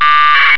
Packet Radio 1200 Bd (13 kB)
Dieses Modem setzt die Bits in Töne unterschiedlicher Frequenz um, die auf den Modulatoreingang eines Funkgerätes gegeben werden.
PacketRadio_1200Baud.wav